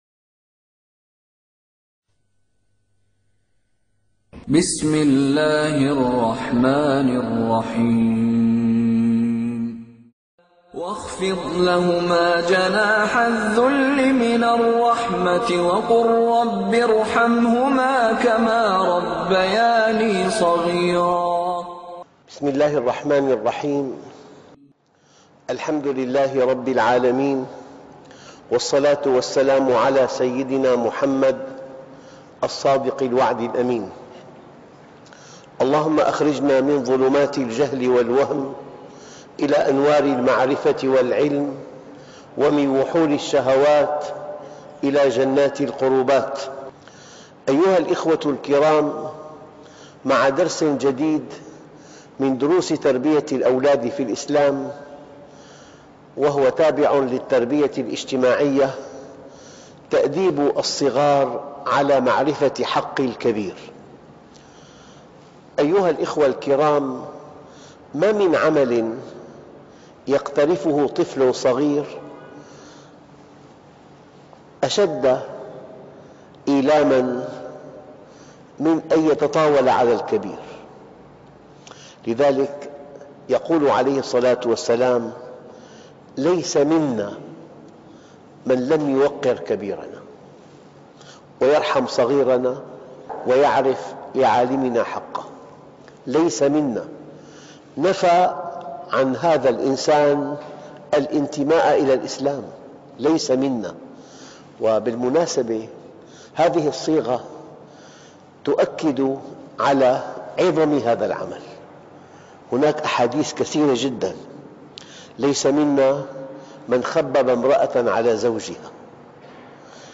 ( الدرس 30 ) التربية الإجتماعية " تأديب الصغار على معرفة حق الكبير " ( 9/4/2009 ) - الشيخ محمد راتب النابلسي - الطريق إلى الله
( الدرس 30 ) التربية الإجتماعية